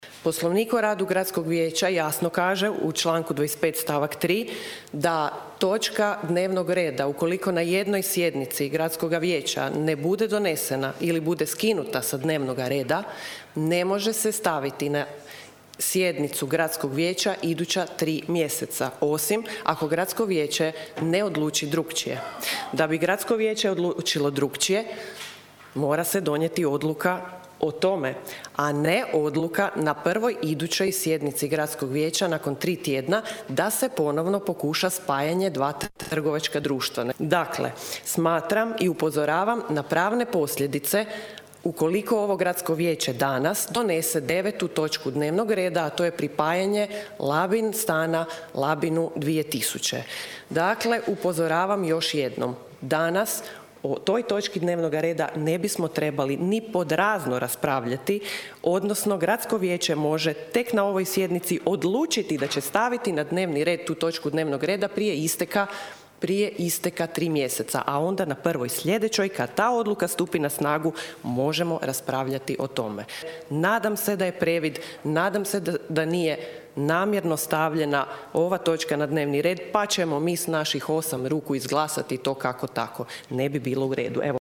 Današnja sjednica Gradskog vijeća Labina započela je više nego zanimljivo.
ton – Federika Mohorović Čekada), pojasnila je vijećnica Mohorović Čekada.